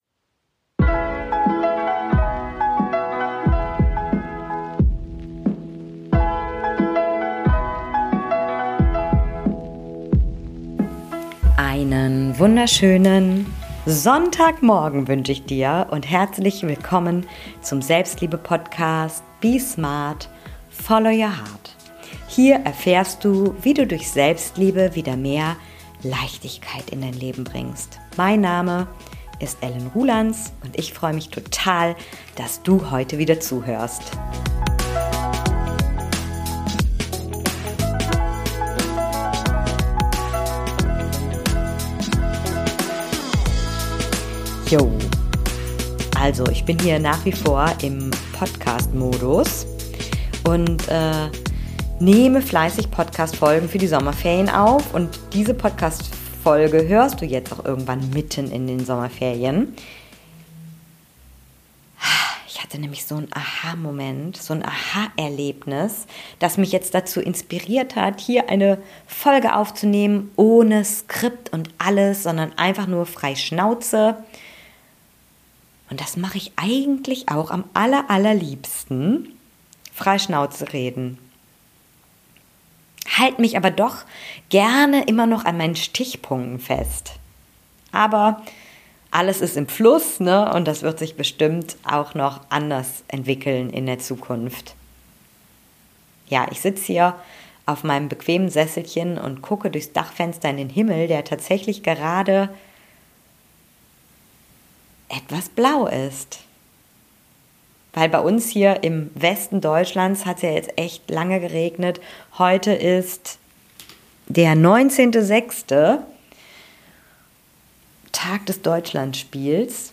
Frei Schnauze ohne Skript geht es um ein persönliches AHA Erlebnis als ich die Stärke in einer meiner Schwächen entdeckte.